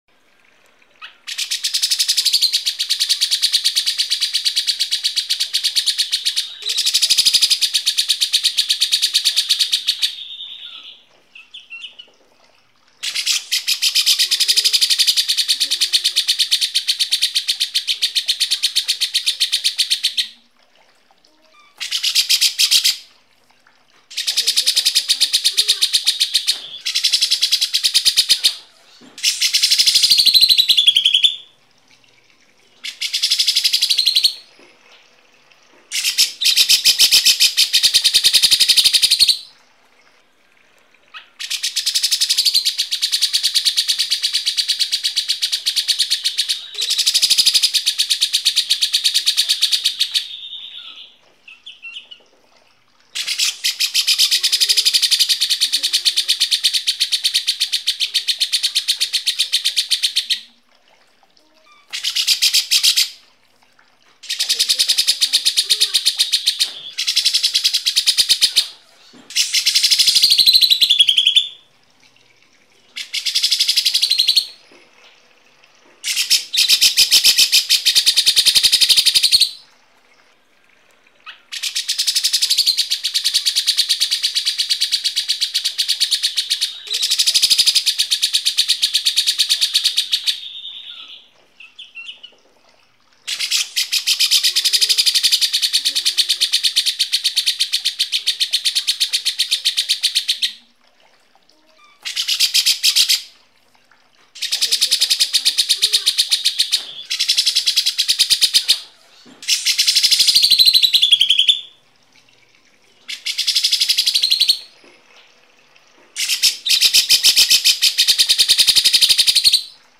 Download suara Burung Cucak Jenggot gacor versi pendek 1 jam dengan isian speed rapat. Suara full beset ini siap melatih burung Anda agar semakin aktif dan bertenaga...
Suara Cucak Jenggot Speed Rapat
suara-burung-cucak-jenggot-speed-rapat-id-www_tiengdong_com.mp3